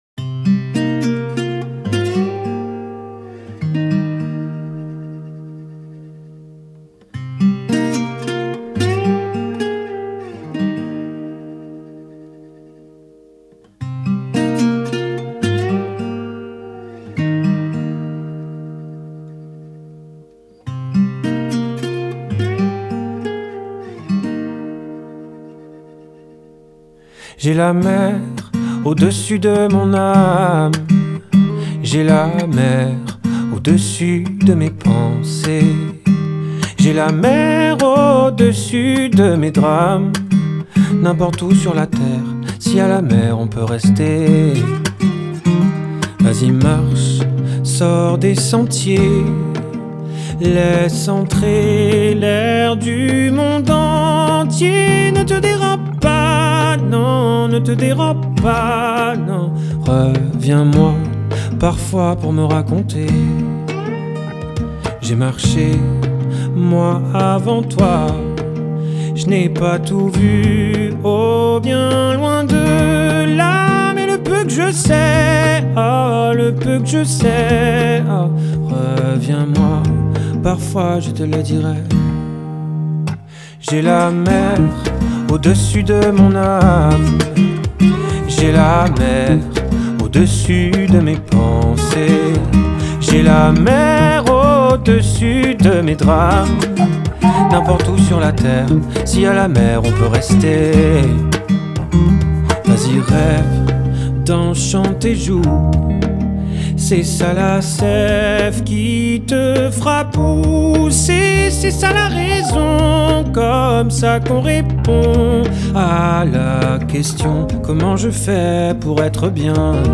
DOUCEUR MUSICALE